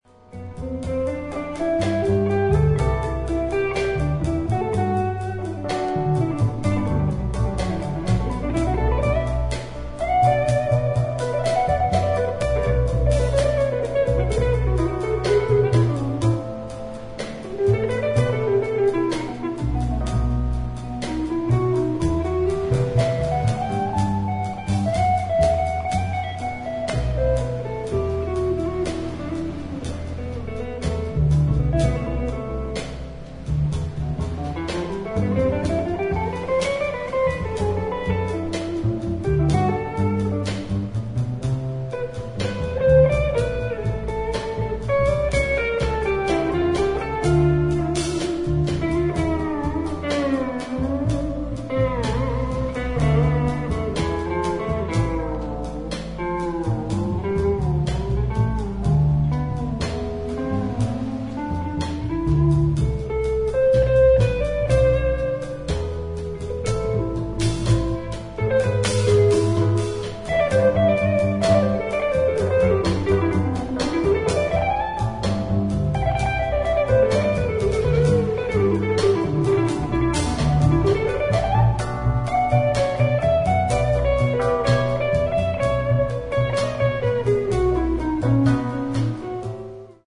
本作は、フェロー諸島にある断崖の渓谷の洞窟でレコーディングされたライヴ音源。